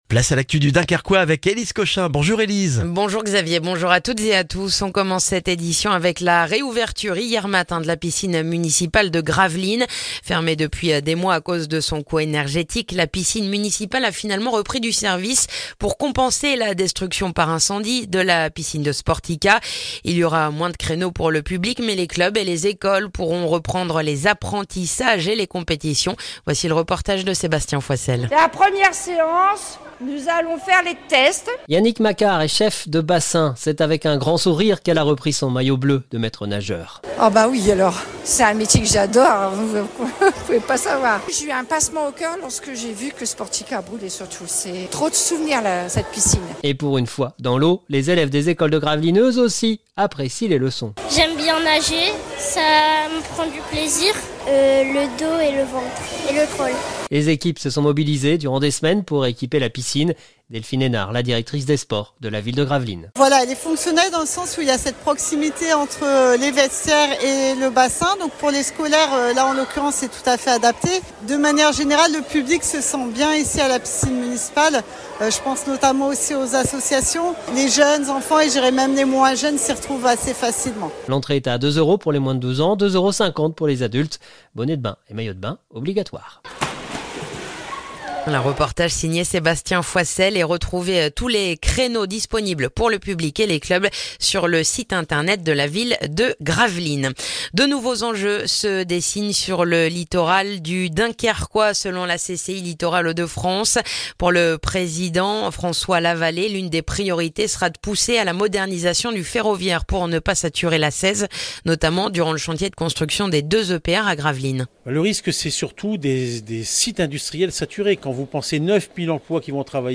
Le journal du mercredi 7 février dans le dunkerquois